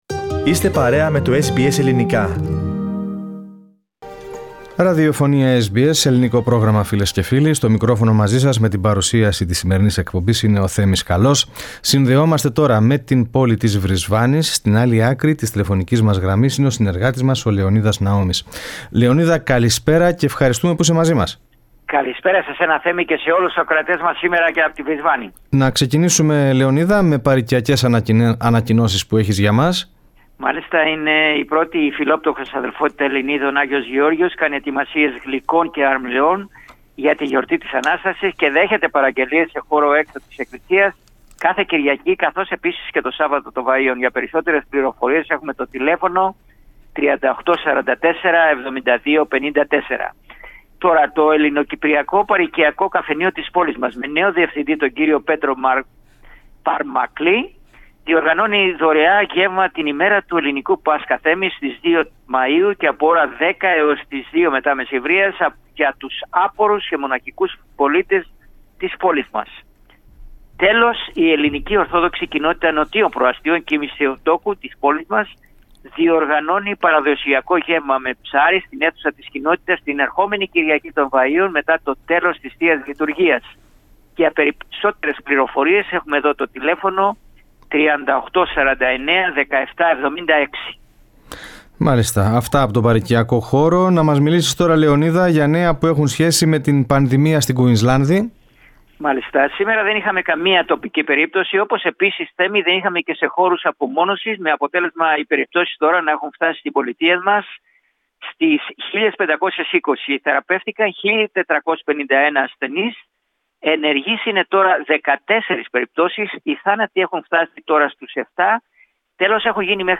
στην ανταπόκρισή του απ΄την Πολιτεία του Φωτός του Ηλίου.